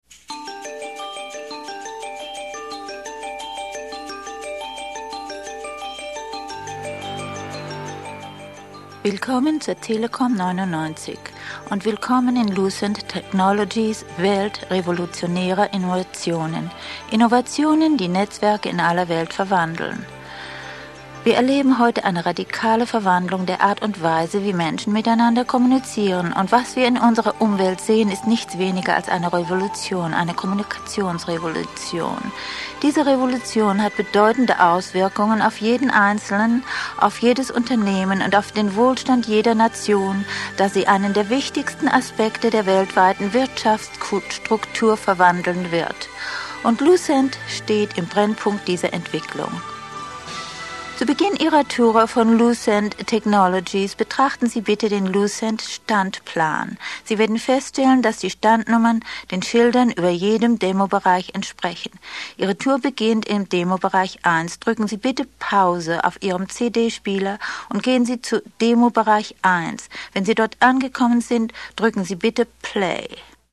Region/Dialect German
Gender Female
Age 60 - 70
Voice Sample